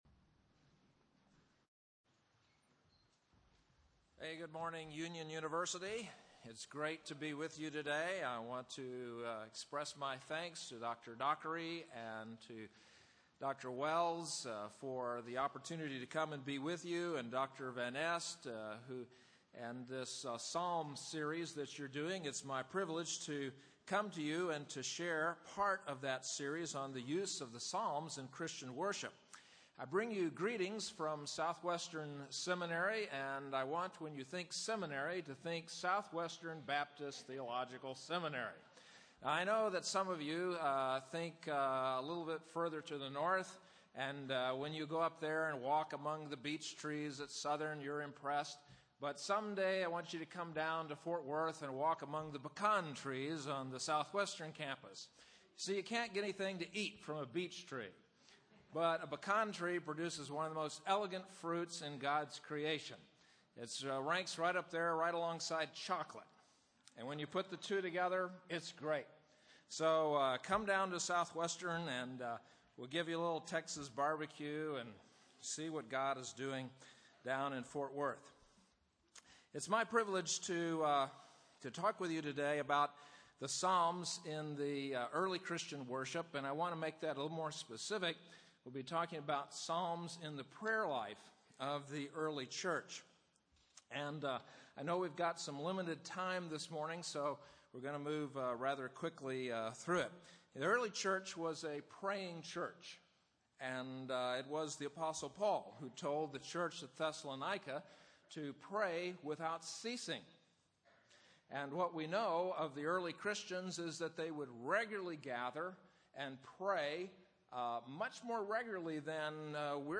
Chapel
Address: The Psalms in Early Christian Worship